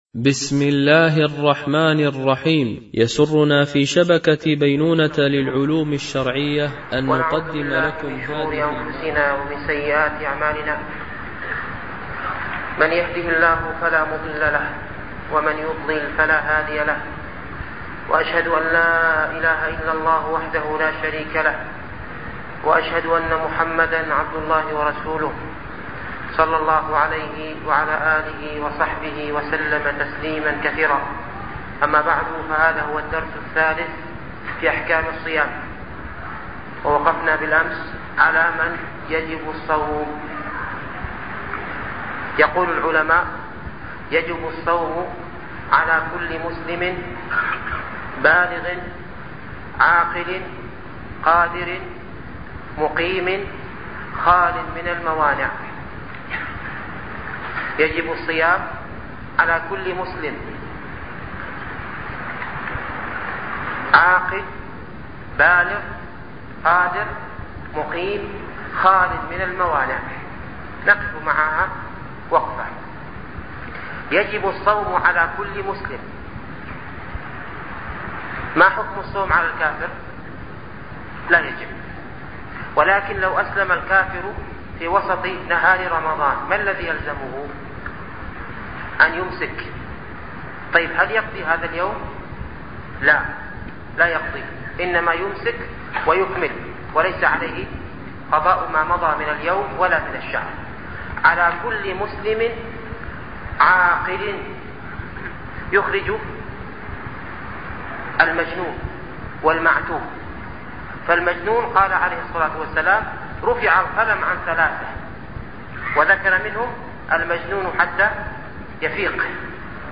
دروس رمضانية ـ الدرس الثالث
MP3 Mono 22kHz 32Kbps